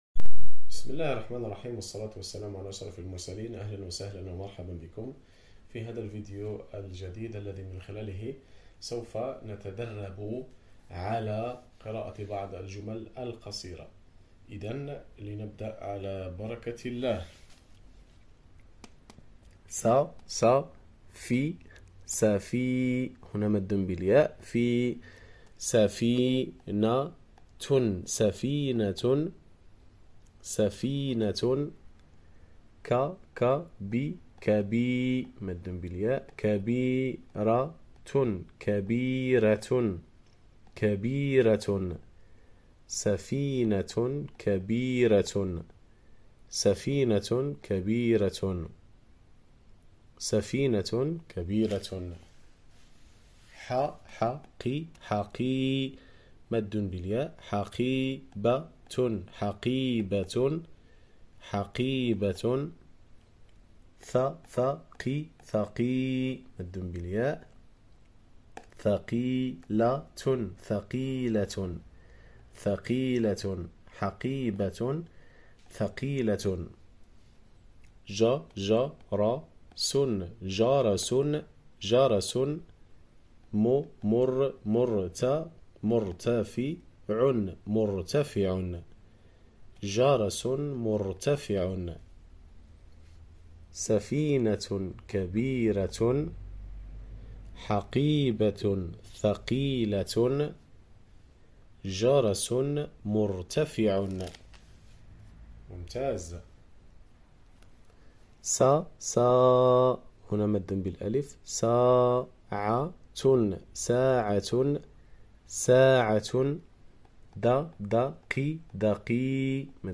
Learning Arabic Language ✅ practice reading the Arabic sentences